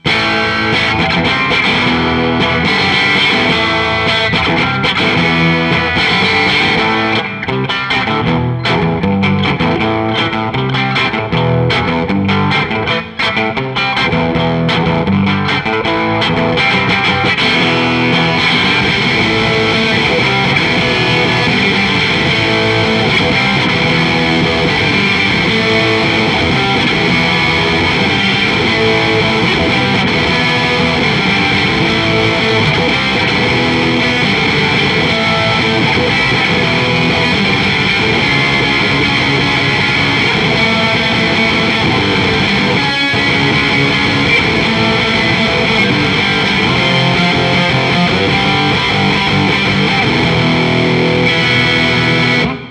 Treble 10, mid similar, bass lower.